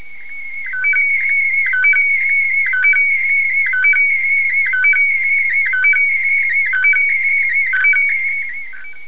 The transmitter and other equipment translated the image into a warbling tone, which distant receiving equipment translated back to an image.
Click on the transmitter to hear the sound of a newsphoto being sent.